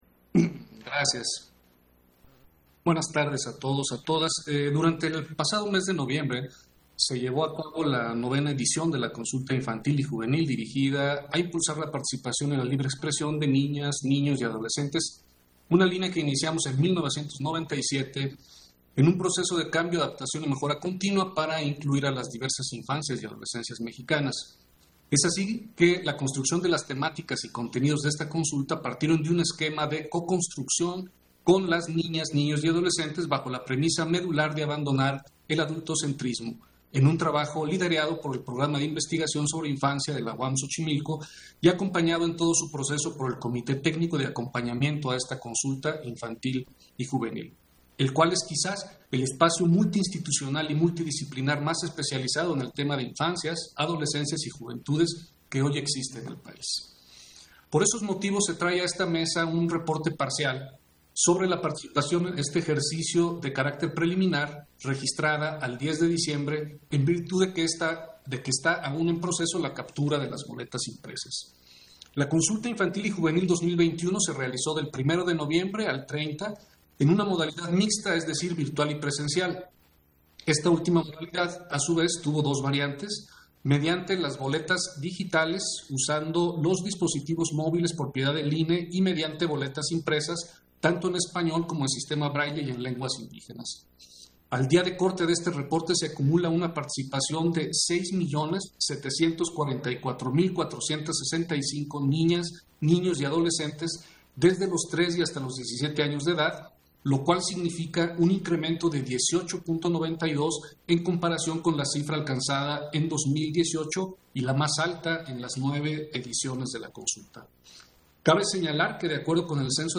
171221_AUDIO_INTERVENCIÓN-CONSEJERO-FAZ-PUNTO-23-SESIÓN-EXT. - Central Electoral